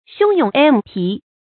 洶涌淜湃 注音： ㄒㄩㄥ ㄩㄥˇ ㄆㄥˊ ㄆㄞˋ 讀音讀法： 意思解釋： 同「洶涌彭湃」。